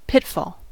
pitfall: Wikimedia Commons US English Pronunciations
En-us-pitfall.WAV